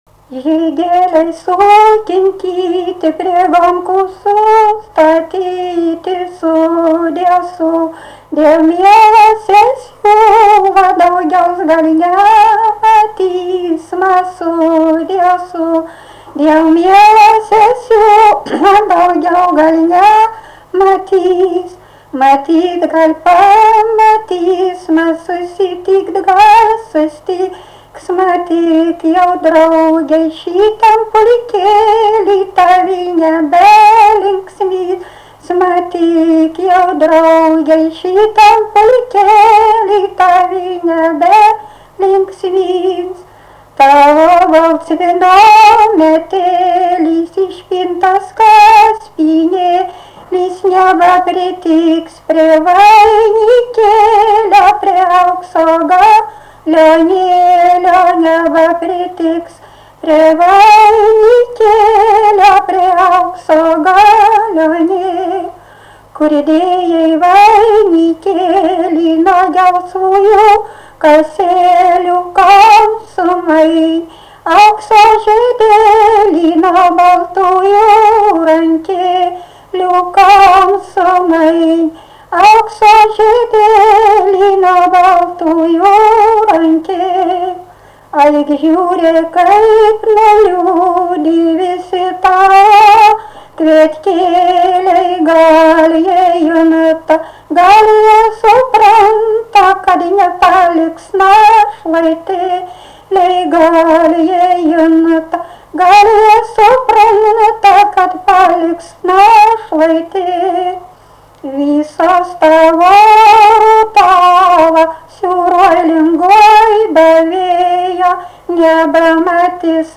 daina, vestuvių
Erdvinė aprėptis Suvainiai
Atlikimo pubūdis vokalinis